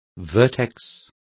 Complete with pronunciation of the translation of vertex.